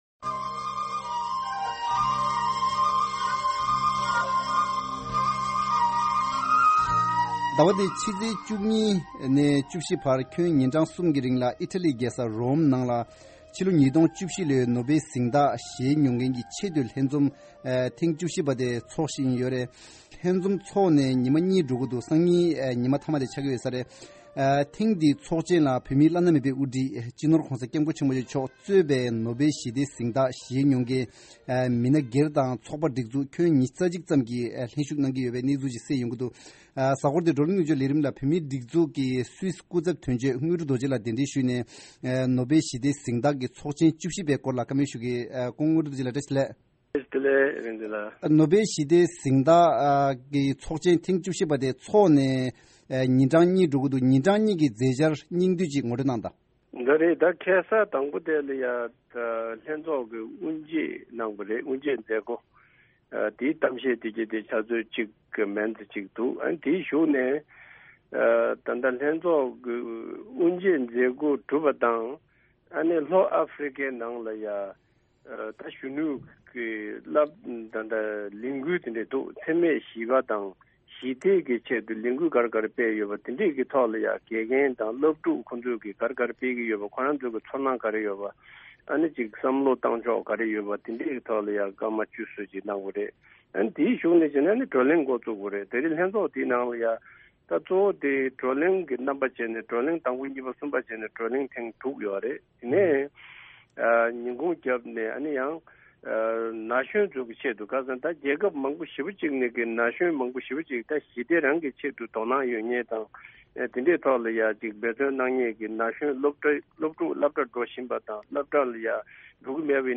བགྲོ་གླེང་མདུན་ལྕོག་ལས་རིམ་གྱིས་ཚོགས་ཆེན་གྱི་གལ་གནད་སྐོར་གླེང་སློང་ཞུ་གི་རེད།